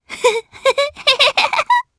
Viska-Vox_Happy2_jp.wav